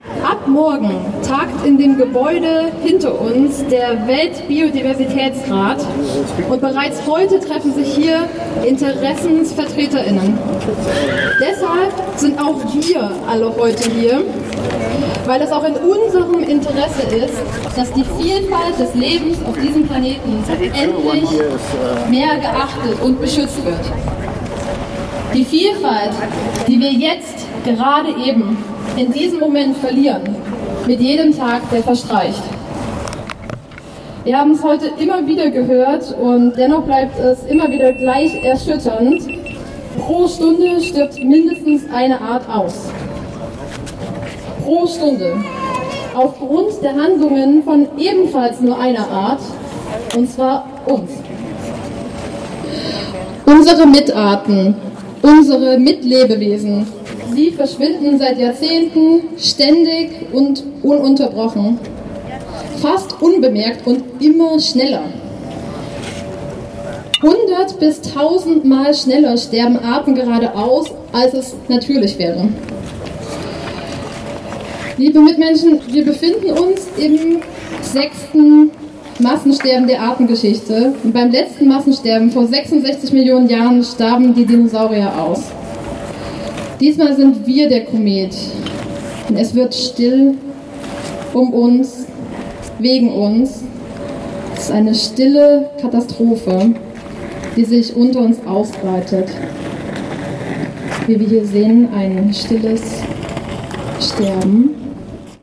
Abschlusskundgebung
Die Einleitungsrede zum „Die-in“